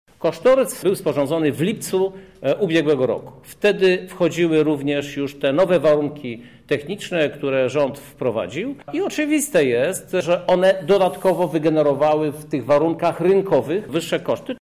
Mówi Krzysztof Żuk, prezydent Lublina.